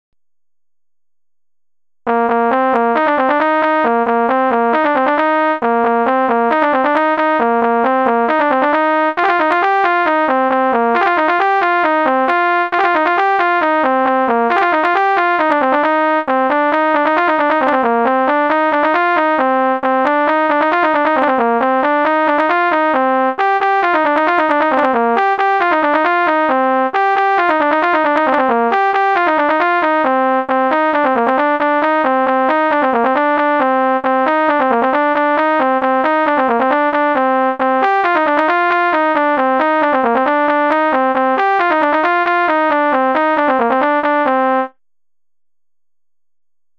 C'est une formation musicale typique de la Bretagne, composée de trois pupitres (cornemuses, bombardes et percussions), créée à la fin de la seconde guerre mondiale en s'inspirant du pipe band écossais qui associe cornemuses et caisses claires.
Notre bagad
Koroll Tro Leur, The Green Hills of Tyrol, When The Battle's Over (airs à marcher), Hanter Dro, An Dro, Kost ar c'hoat, Pach Pi, Kas a Barh (airs à danser), The Water is wide, The Bells of Dumblane, Highland Cathedral, Amazing Grace, My home in the Hills (airs écossais)